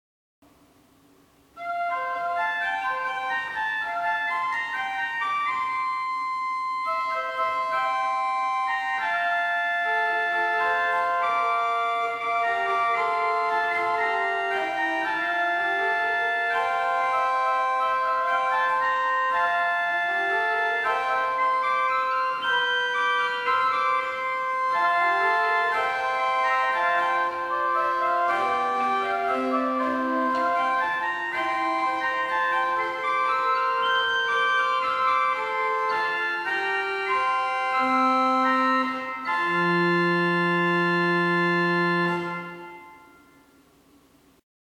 The sound, in fact, is very similar to a Tannenberg organ.
Listen to an improvisation on the Principal 4' by clicking